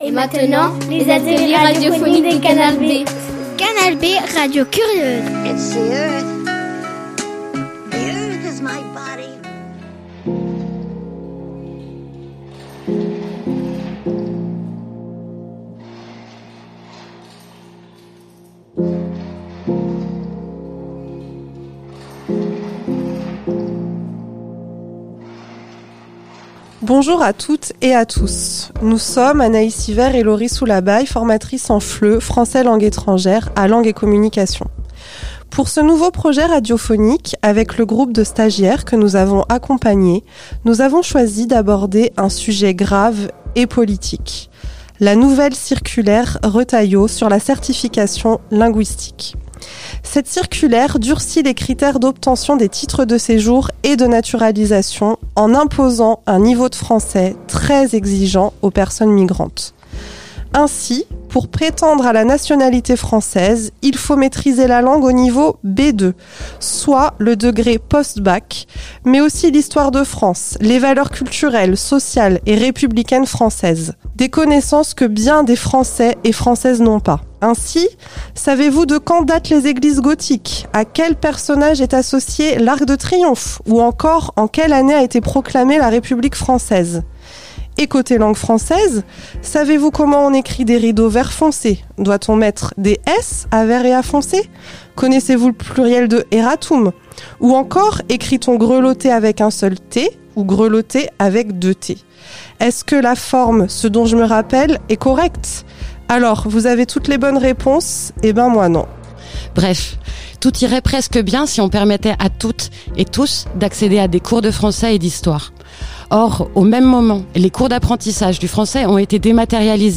Alors, pour ce nouveau projet radio mené avec des stagiaires de Langue et Communication – personnes migrantes en apprentissage du français – on a décidé d’aborder ce sujet grave et politique.